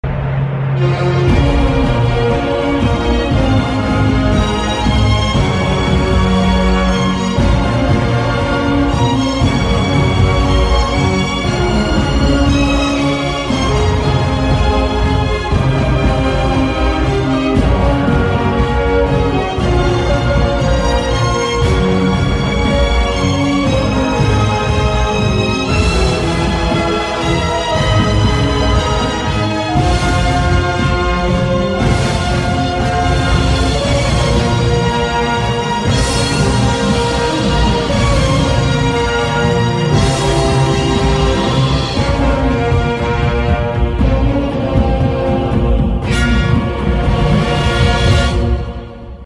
• Качество: высокое
Краткая инструментальная версия